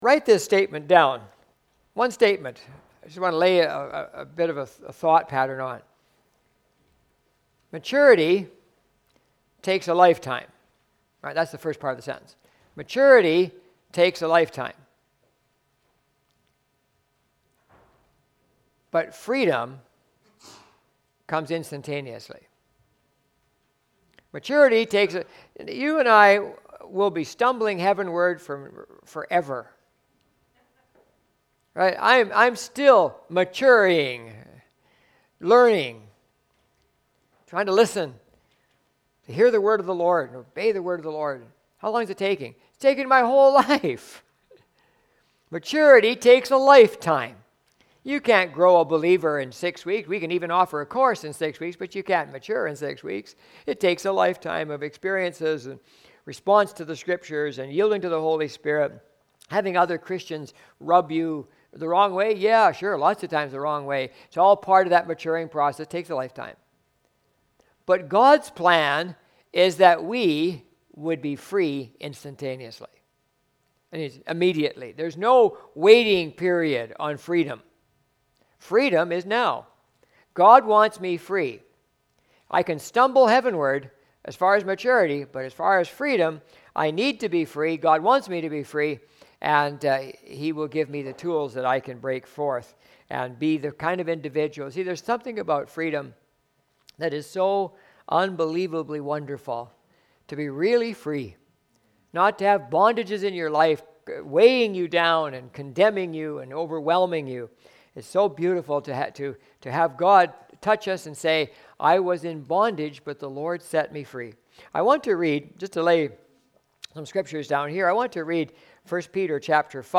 Valley Church - Lynn Valley - North Vancouver - Sermons